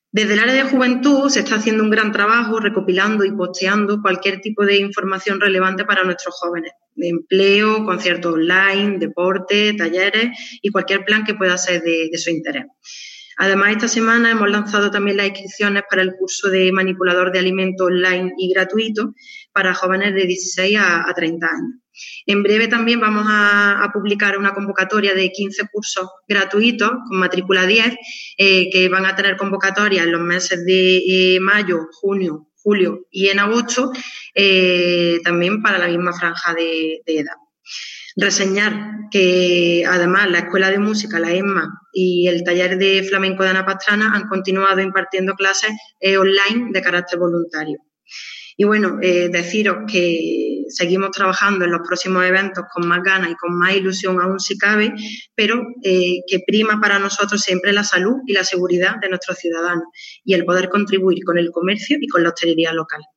Unos versos del celebérrimo poeta antequerano José Antonio Muñoz Rojas iniciaban en la mañana de hoy jueves 23 de abril, Día del Libro, la rueda de prensa que tanto el alcalde de Antequera, Manolo Barón, como la teniente de alcalde Elena Melero han protagonizado para ofrecer un balance de actividad del Área de Cultura, Ferias, Tradiciones y Juventud durante el periodo de Estado de Alarma que aún sigue vigente.
Cortes de voz